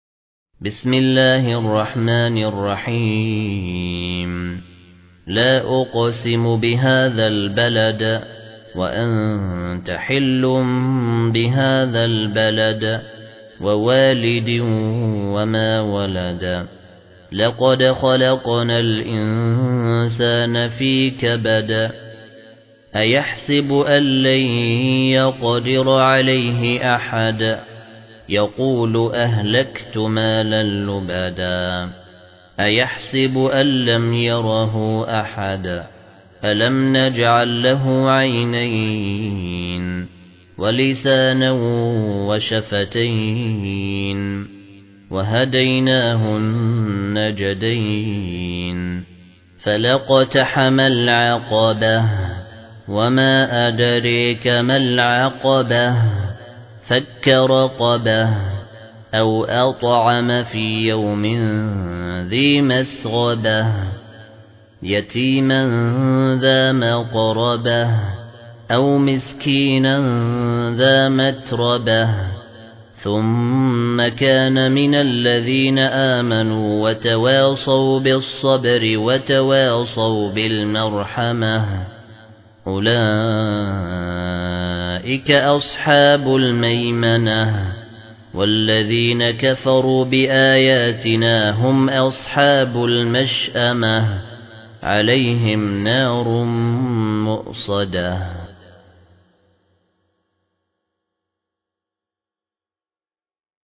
المصحف المرتل - الدوري عن أبي عمرو البصري